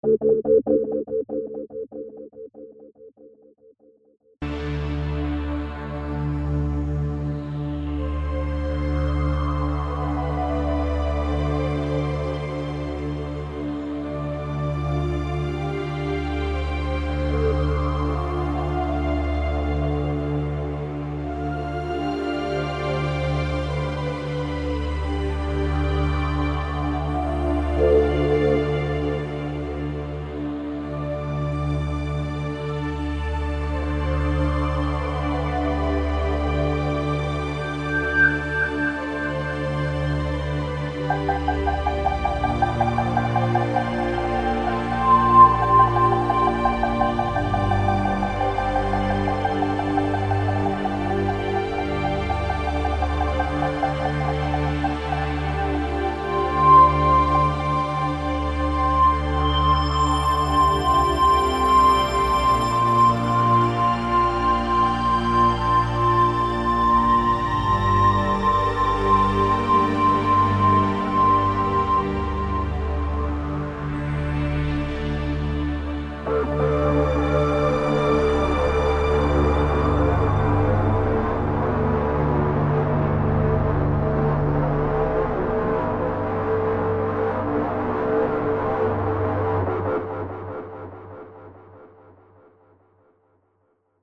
chilout1.mp3